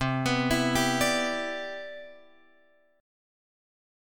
C Major 9th